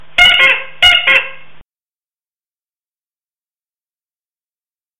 hippobike_horn.mp3